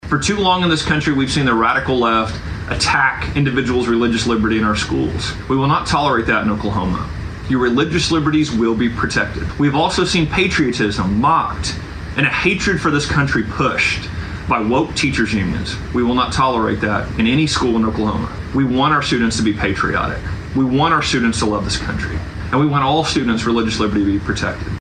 Walters explains what the goal of that department will be.